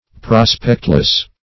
Prospectless \Pros"pect*less\, a. Having no prospect.